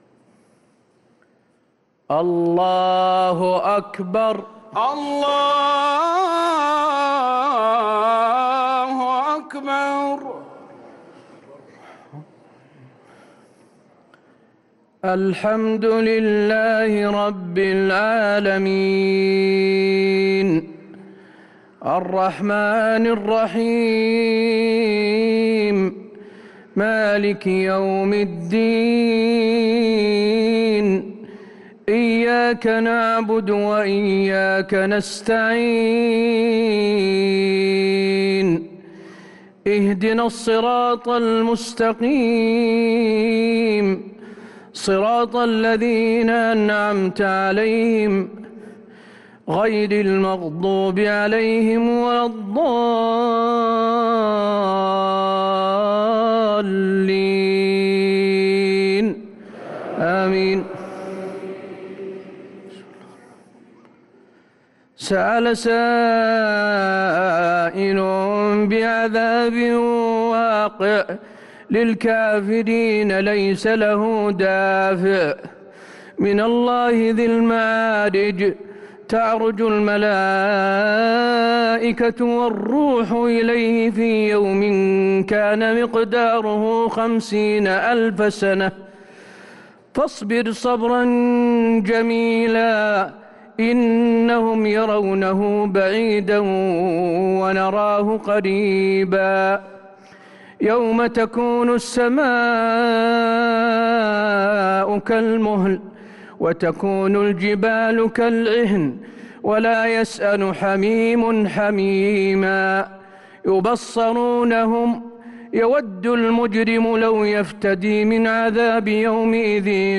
صلاة العشاء للقارئ حسين آل الشيخ 15 رمضان 1444 هـ
تِلَاوَات الْحَرَمَيْن .